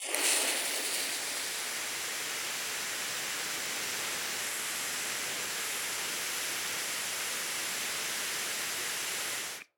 Terrarum/assets/mods/basegame/audio/effects/explosion/fuse.ogg at 5da4cee22e2ca73e6cd1a6cb7ef122ce182c280d
fuse.ogg